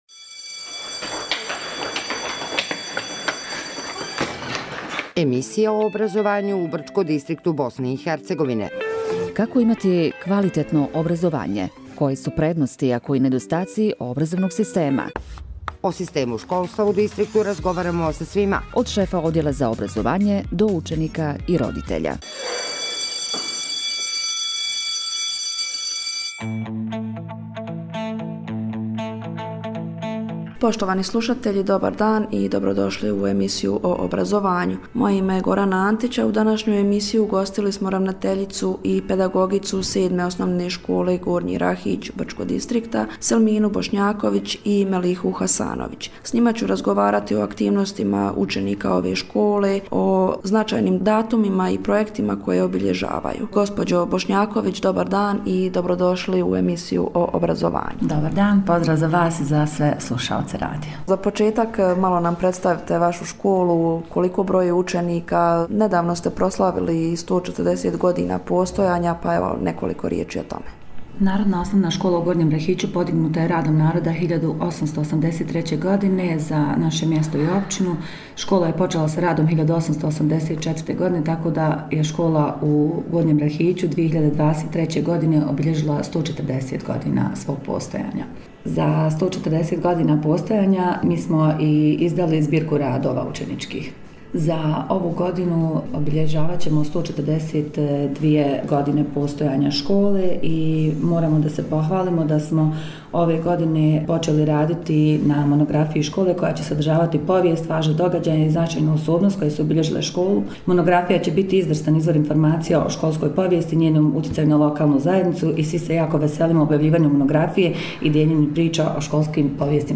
U emisiji o obrazovanju razgovarali smo sa djelatnicama Sedme osnovne škole o aktivnostima učenika i značajnim projektima u kojima su sudjelovali.